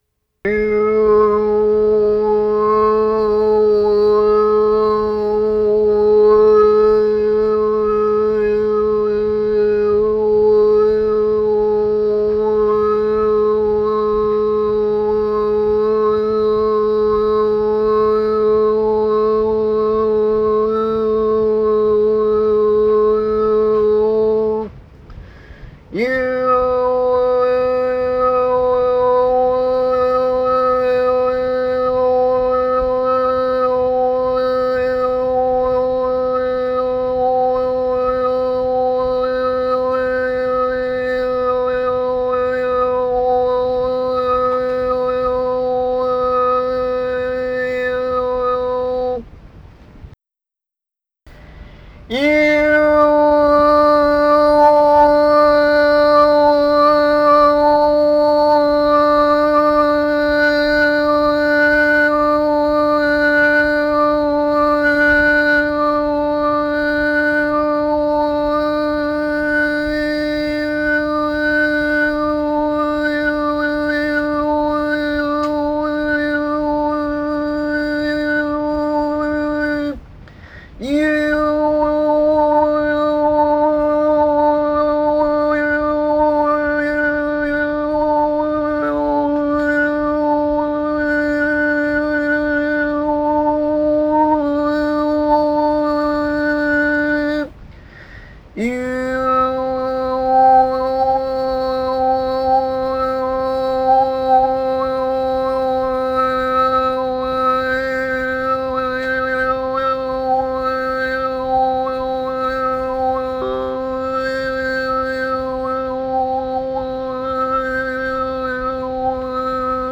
(1)ホーミー練習(15.2MB)
コメント：「ホーミーは大部うまくなったでしょ。